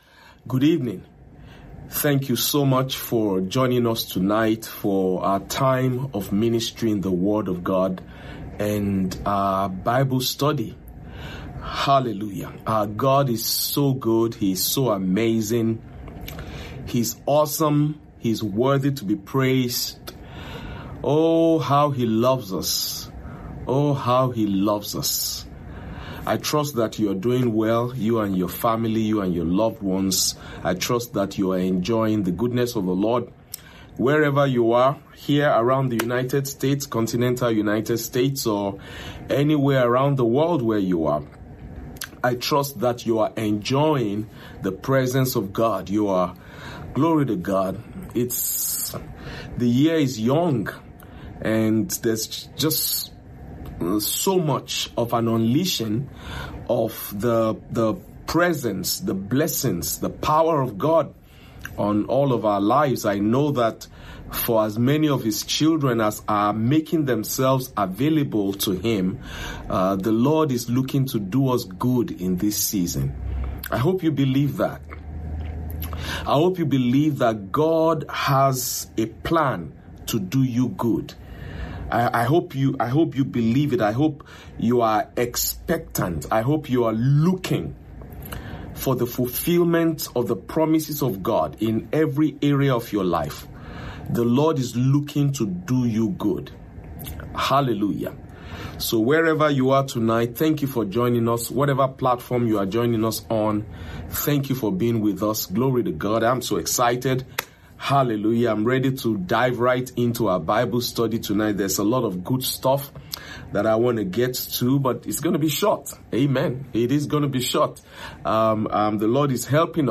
Midweek Service